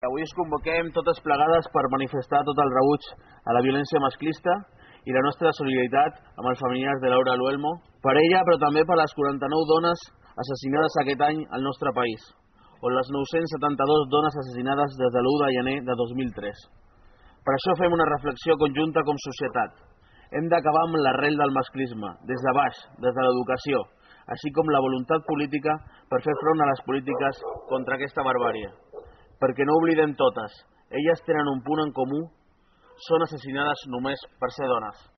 El vespre d’aquest dijous, la plaça de l’Ajuntament de Palafolls va tornar a ser l’escenari d’una nova concentració de protesta contra la violència masclista.
La convocatòria, feta des del grup municipal de Palafolls en Comú, va comptar amb la lectura d’un manifest.